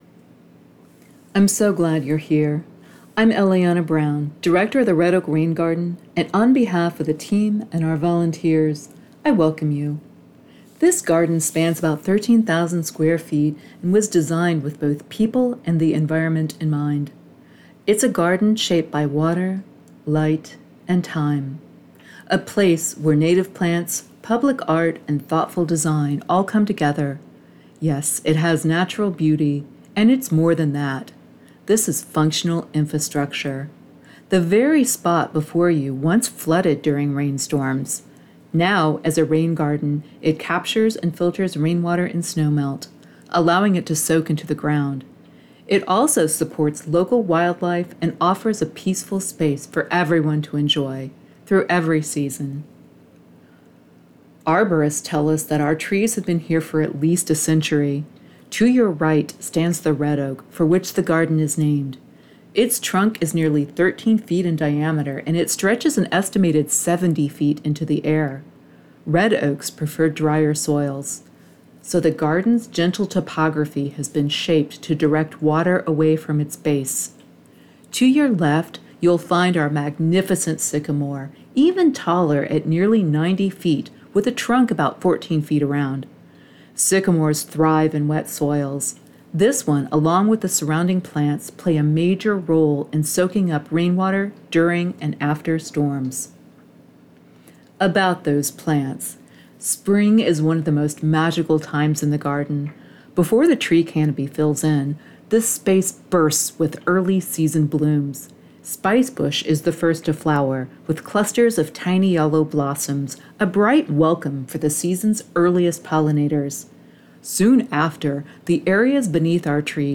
audio tour.
audio-tour.m4a